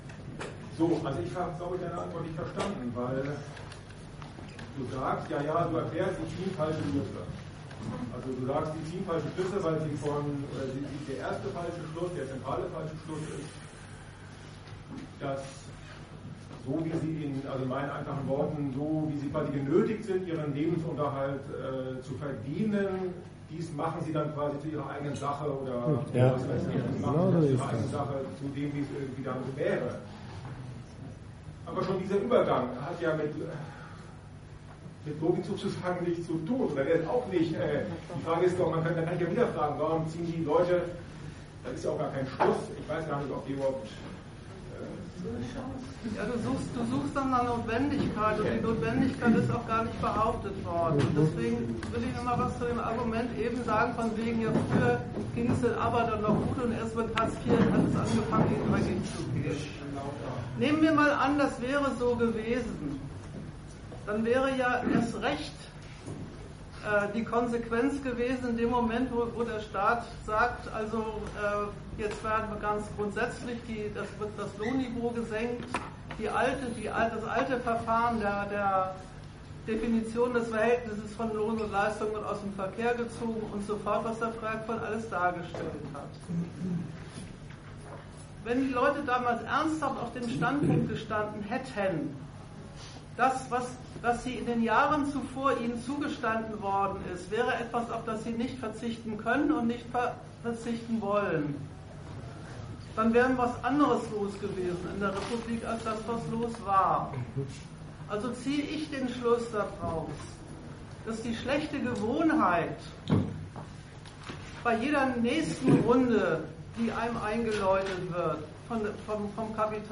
Datum 20.02.2014 Ort Bremen Themenbereich Arbeit, Kapital und Sozialstaat Veranstalter Argudiss Dozent Gastreferenten der Zeitschrift GegenStandpunkt Die Einführung eines Mindestlohns von 8,50€ flächendeckend ist von der Großen Koalition beschlossen.